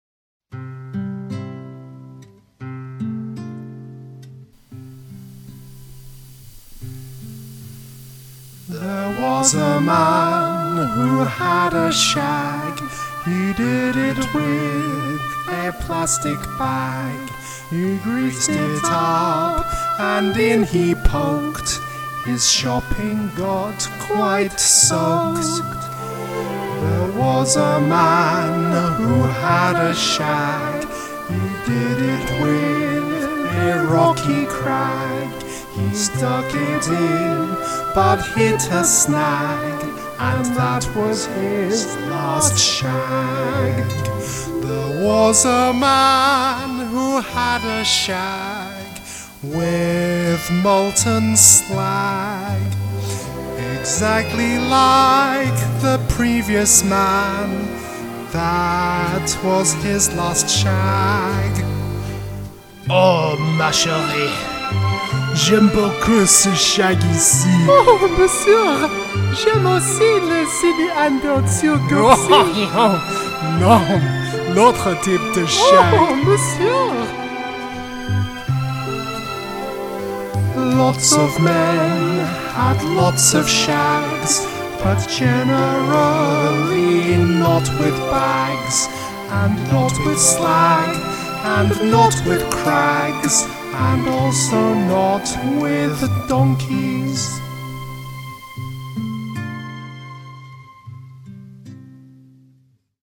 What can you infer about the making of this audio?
So anyway, all of these are 'demos' unless we can't be bothered to do anything else with them.